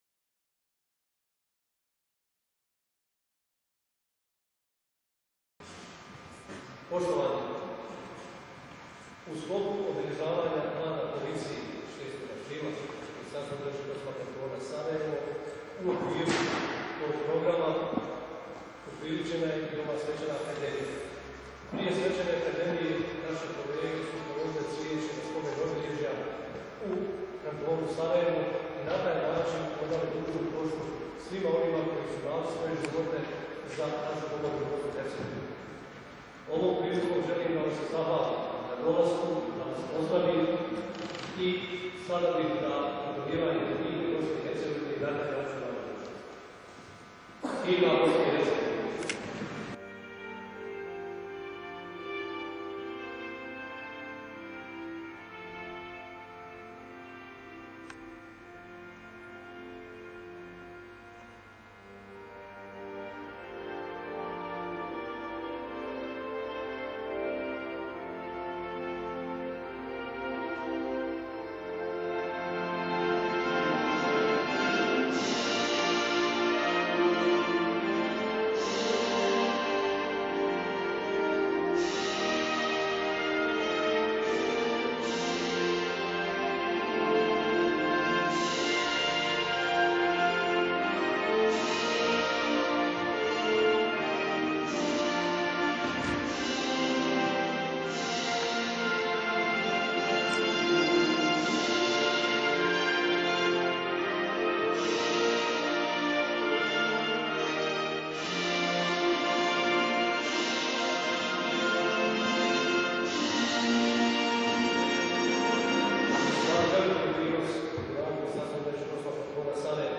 6. april - Dan policije - Svečana akademija
svecana_akademija_0.ogg